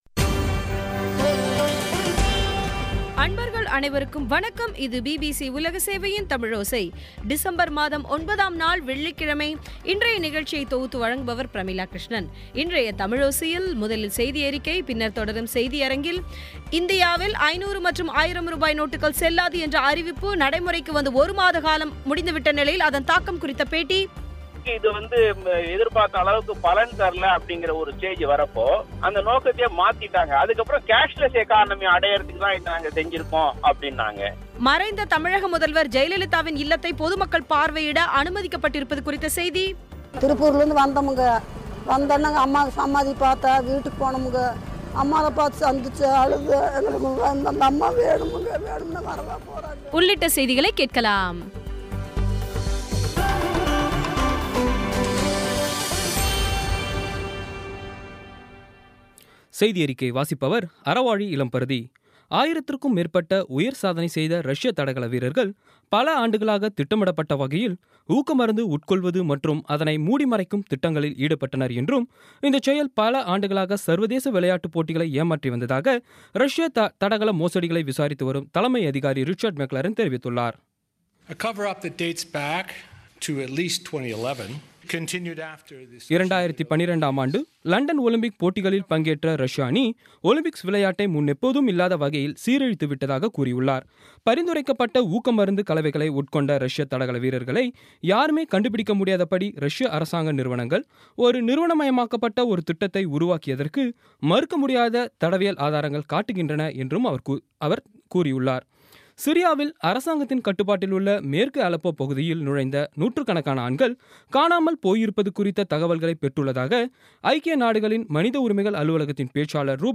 இன்றைய தமிழோசையில், முதலில் செய்தியறிக்கை, பின்னர் தொடரும் செய்தியரங்கத்தில்,